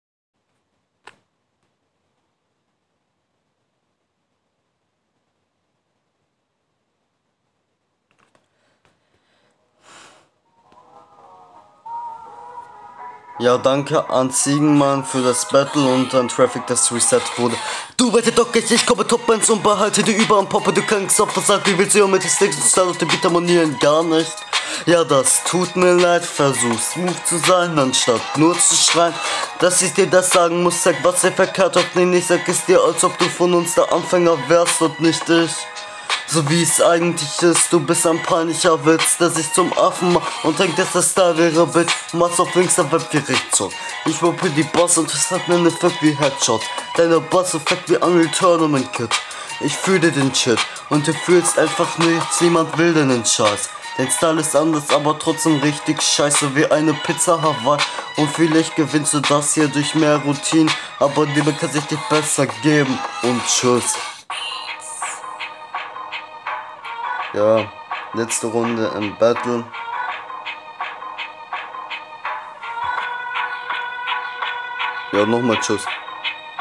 Dein Flow ist eig. ganz gut, ausbaufähig aber cool.